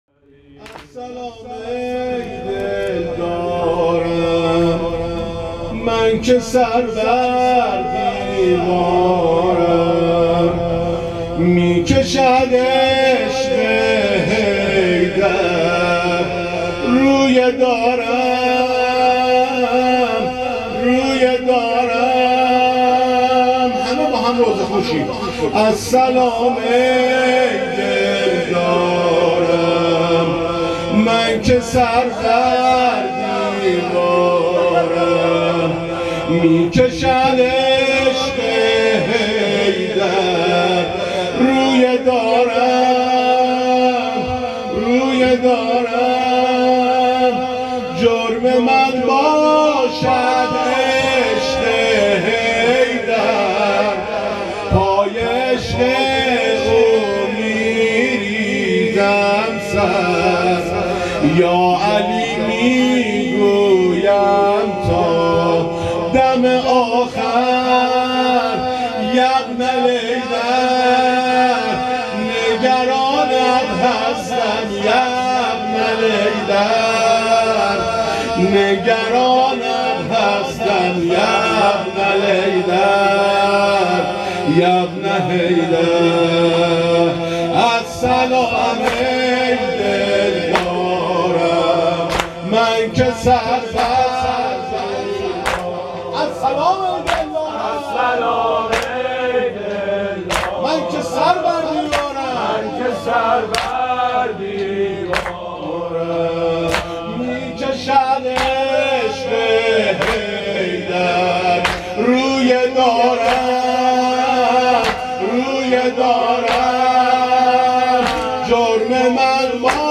زمینه - السلام ای دلدارم من که سر بر دیوارم
شب اول محرم 99